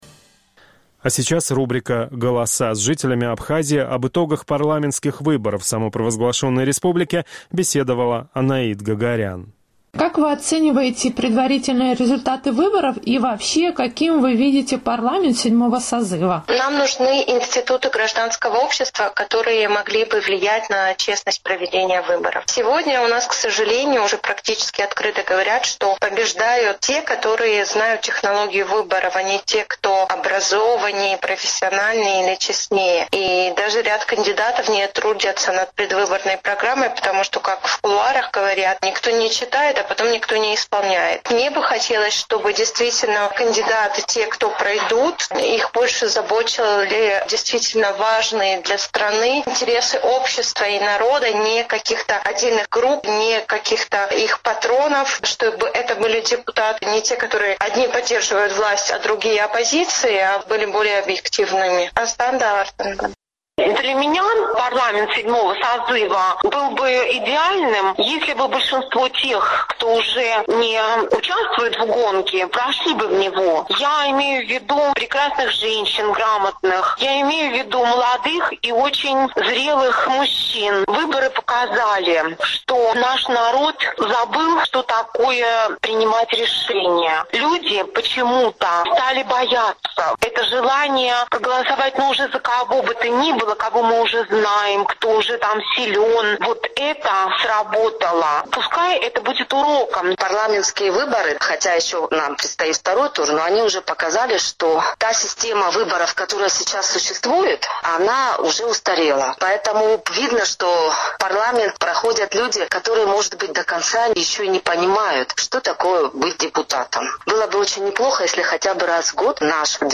Весьма удручающими назвали предварительные результаты выборов в абхазский парламент большинство опрошенных «Эхом Кавказа» местных жителей.